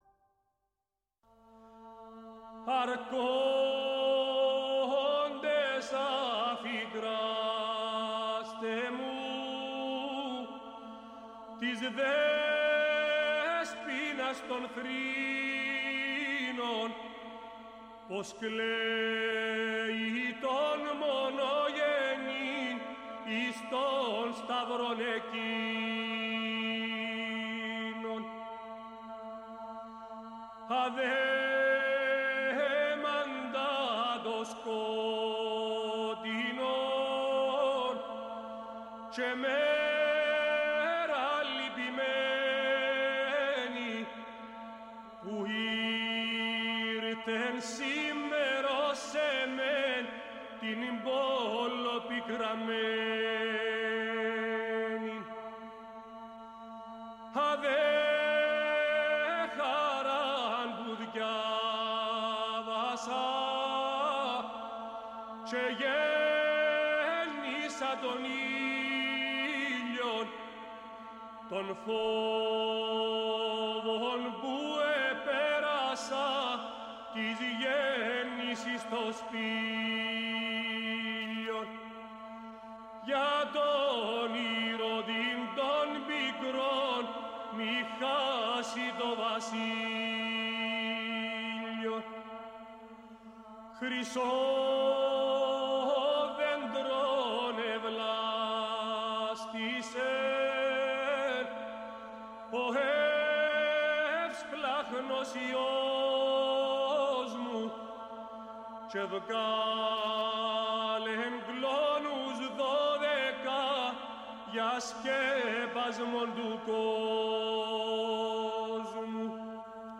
Αμέσως μετά, ανοίξαμε γραμμή με την πρωτεύουσα του Περού, τη Λίμα.